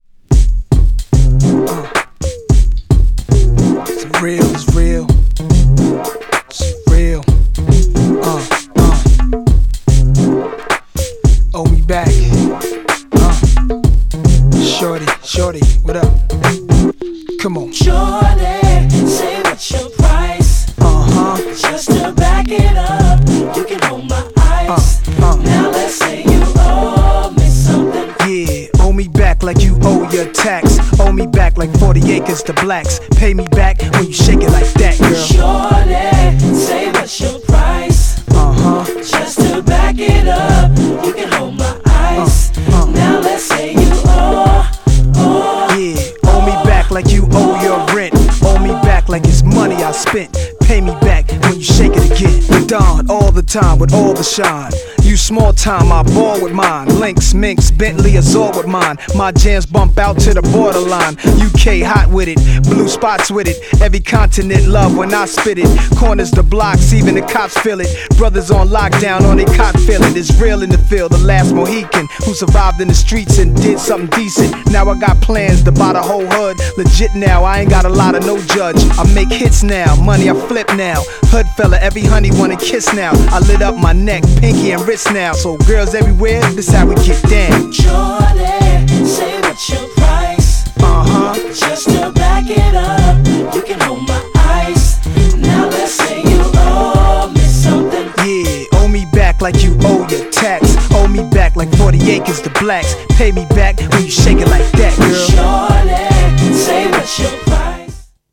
GENRE Hip Hop
BPM 91〜95BPM
featに男性ボーカル
SMOOTHなフロウ
フックでR&Bなコーラスがイイ! # ホッコリしたHIPHOP # メロディアスなHIPHOP